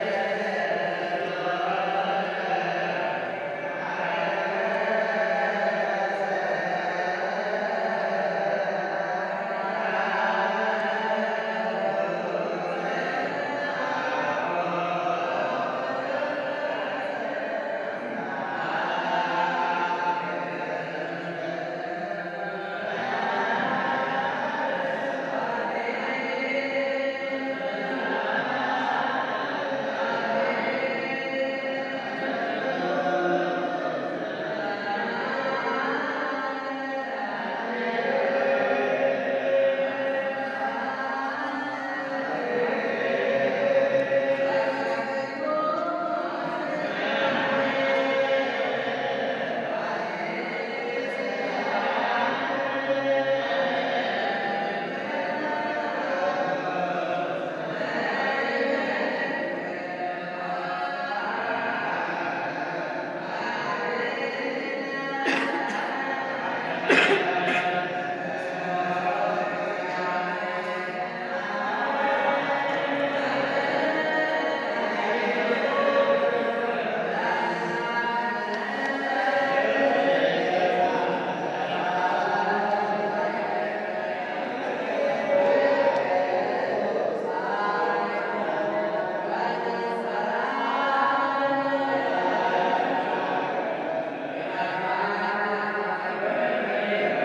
Singing in the Ethiopian Orthodox Haile Selassie Cathedral in Addis Abababa.